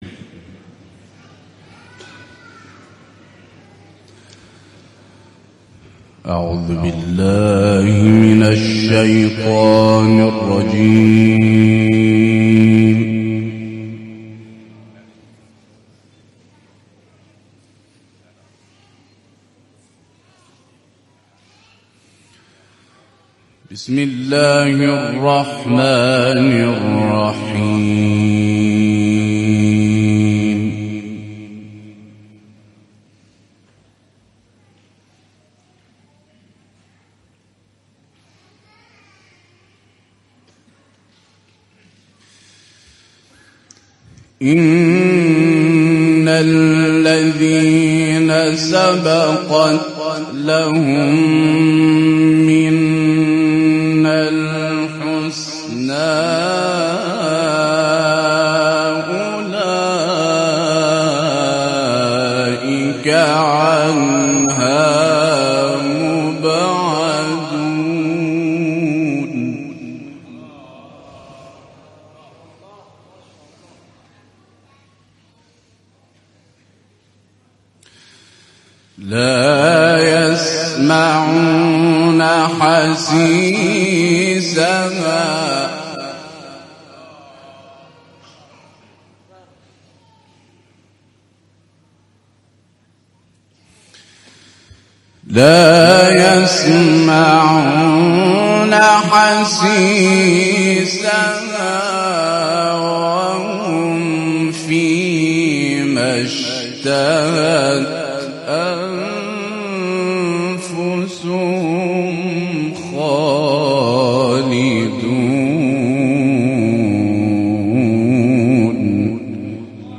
صوت/ تلاوت قراء کاروان انقلاب در مسجد امام حسین(ع) قشم
گروه چندرسانه‌ای ــ کاروان قرآنی انقلاب، یازدهمین روز سفر خود را با حضور در مسجد امام حسین(ع) شهر قشم ادامه داد و قاریان در محفل انس با قرآن به تلاوت آیات نور پرداختند.